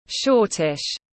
Hơi lùn tiếng anh gọi là shortish, phiên âm tiếng anh đọc là /ˈʃɔː.tɪʃ/ .
Shortish /ˈʃɔː.tɪʃ/